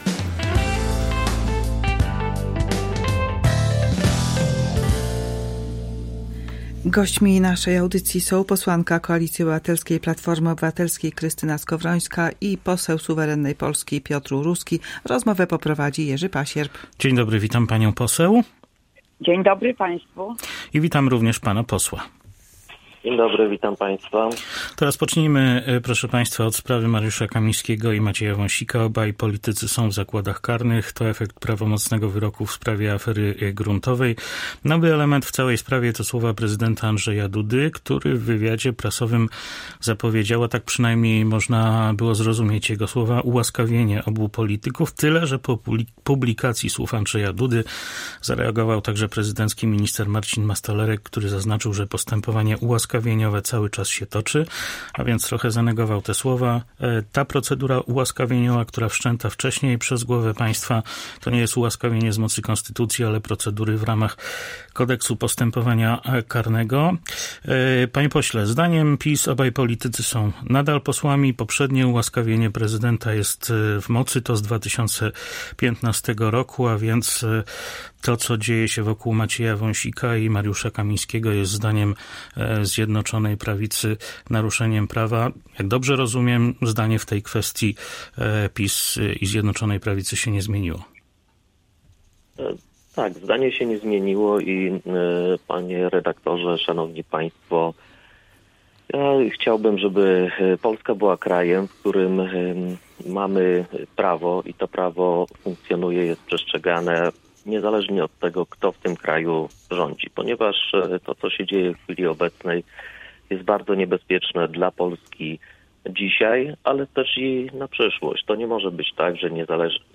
Goście porannego Kalejdoskopu komentują sprawę skazanych polityków Zjednoczonej Prawicy
Sprawę skazanych na karę więzienia polityków Zjednoczonej Prawicy Macieja Wąsika i Mariusza Kamińskiego skomentowali na naszej antenie politycy ugrupowań reprezentowanych w parlamencie, posłanka Krystyna Skowrońska z Platformy Obywatelskiej i poseł Piotr Uruski z Suwerennej Polski.